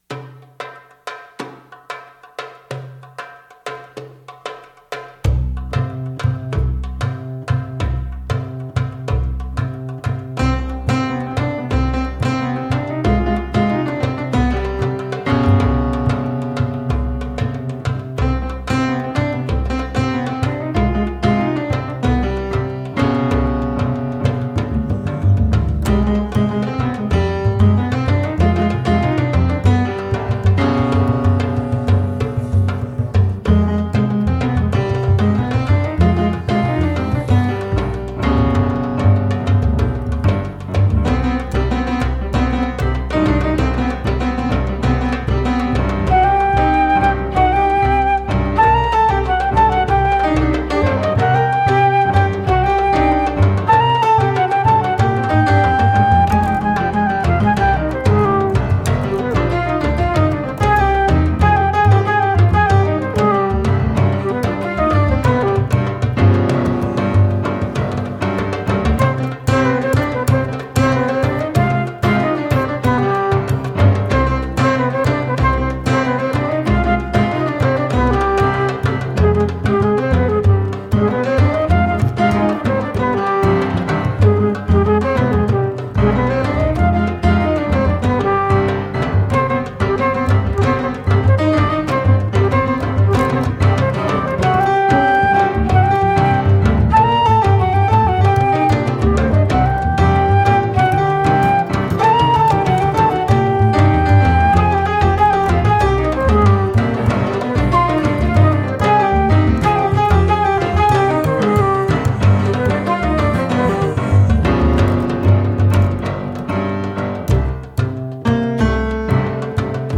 piano
flute
bass
percussion